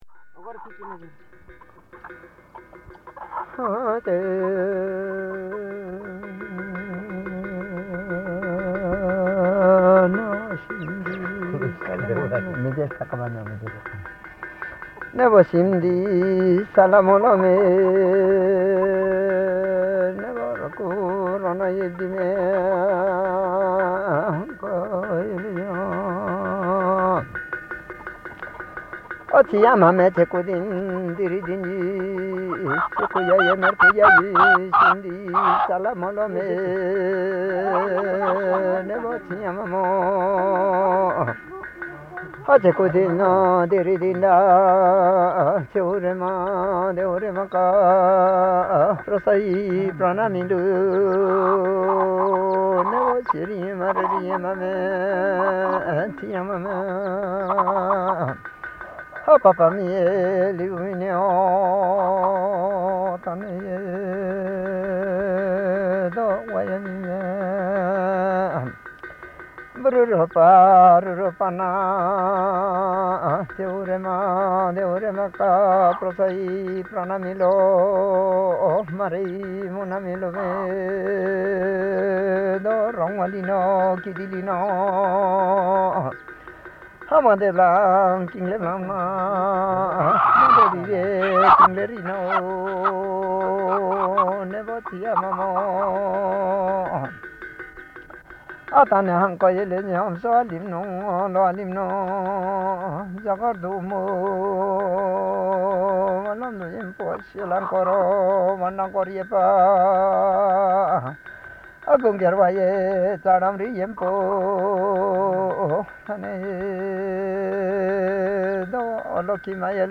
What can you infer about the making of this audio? being from a collection of reel-to-reel recordings of music and spoken language (principally Thulung Rai)